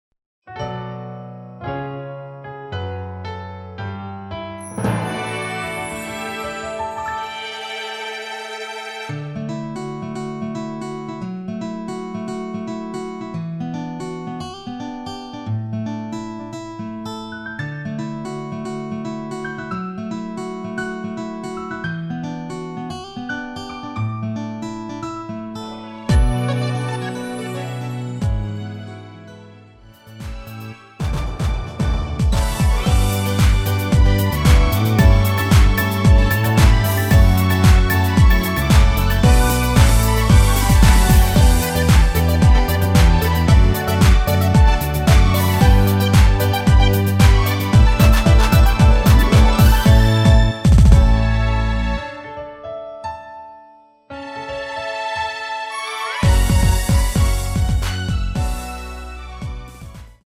올린 MR
노래방에서 음정올림 내림 누른 숫자와 같습니다.
앞부분30초, 뒷부분30초씩 편집해서 올려 드리고 있습니다.
중간에 음이 끈어지고 다시 나오는 이유는